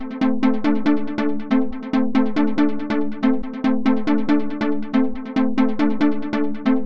令人振奋游戏音效
标签： sfx 音效 设计 令人振奋 游戏 快乐 魔法 合成器 视频 声音
声道立体声